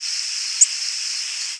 Tennessee Warbler diurnal flight calls
Bird in flight.